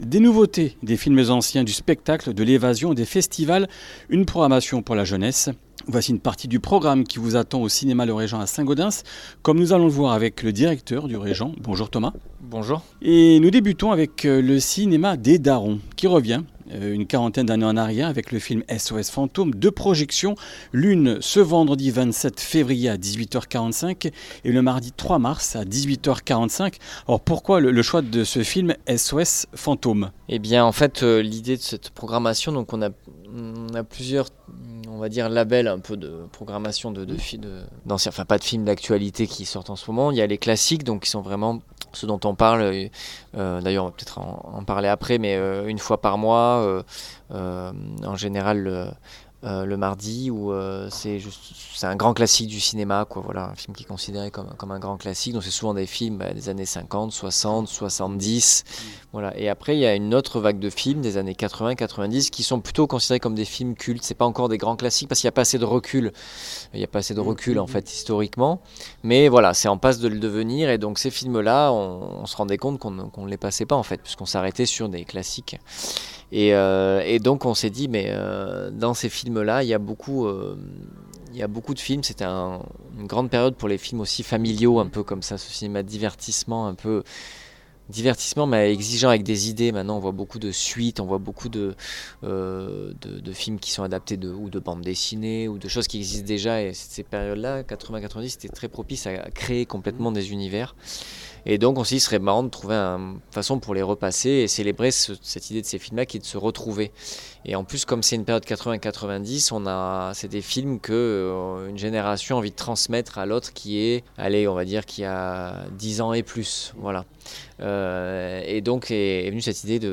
Comminges Interviews du 26 févr.